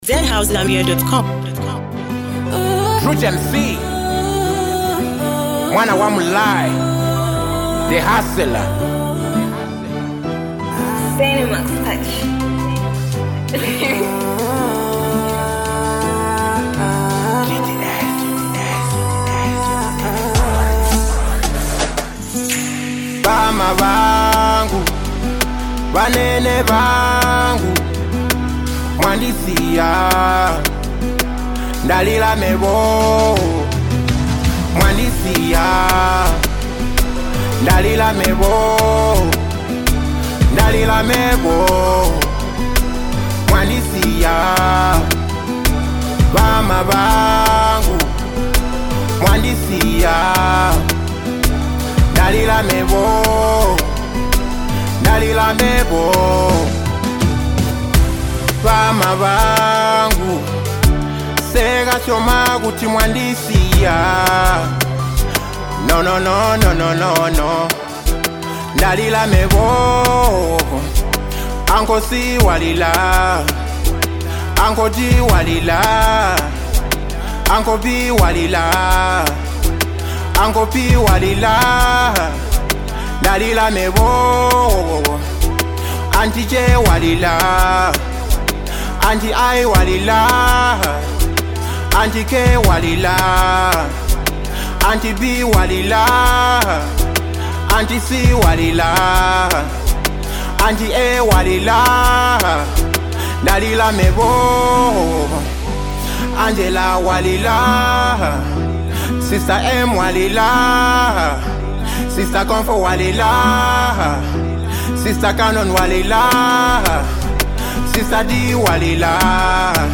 A heartfelt tribute wrapped in melody